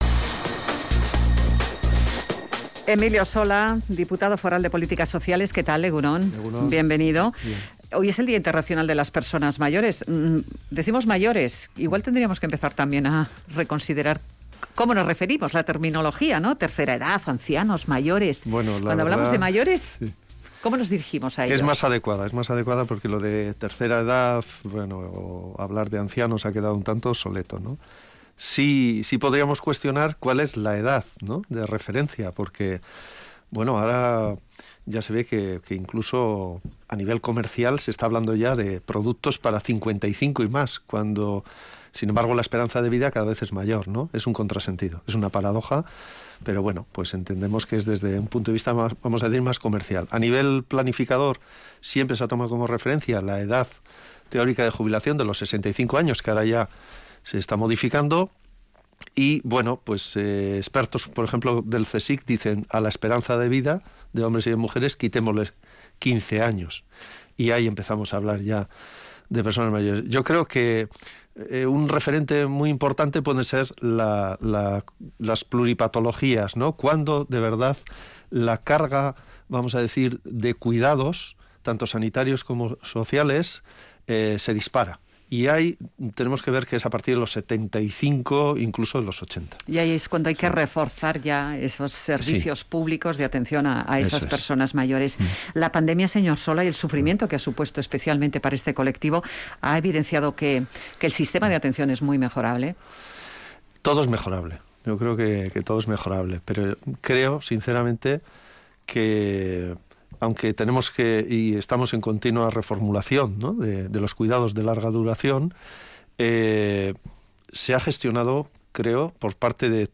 Entrevistado en Radio Vitoria, el diputado foral de Políticas Sociales, Emilio Sola, avanza que desde Diputación están diseñando la creación de un centro de formación para cuidadoras